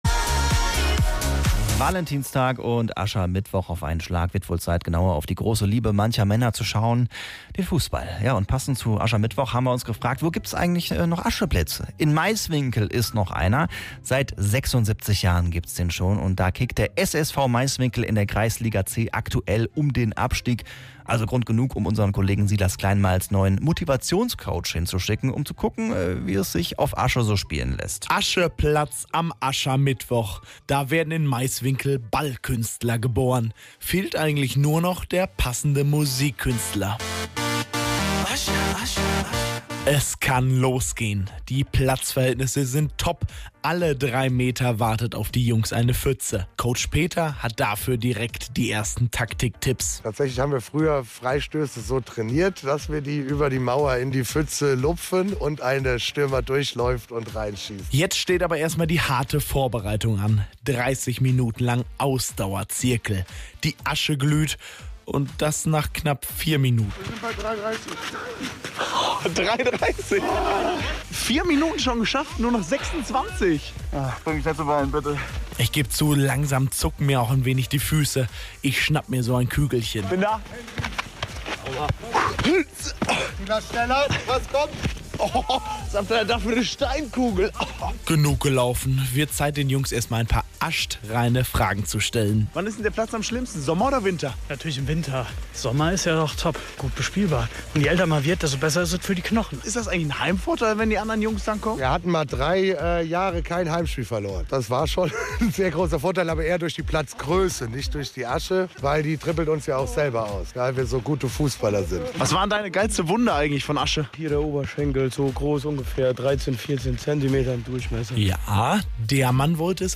Thematisch passend zum Aschermittwoch haben wir einen der letzten Asche-Fußballplätze im Kreis besucht. Beim SSV Meiswinkel wird noch auf der roten Asche gespielt.
auf-dem-ascheplatz-in-meiswinkel.mp3